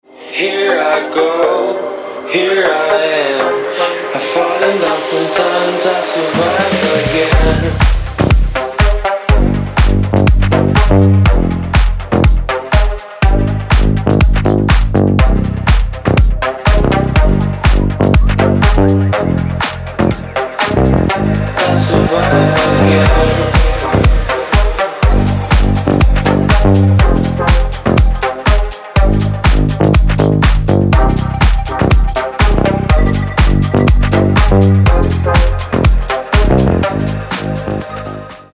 • Качество: 320, Stereo
ритмичные
мужской вокал
deep house
dance
Electronic
EDM
динамичные
Стиль: deep house